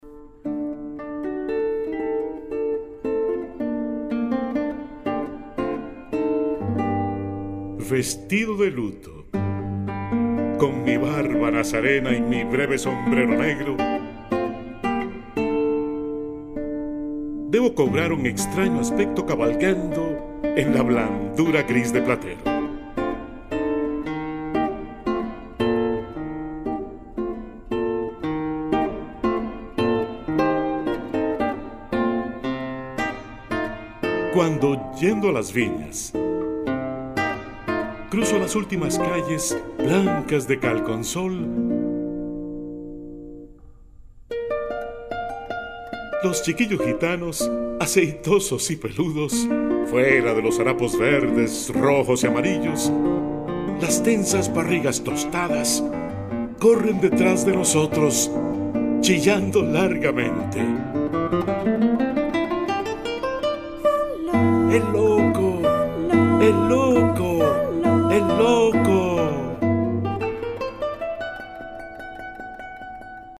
Narrator
Guitar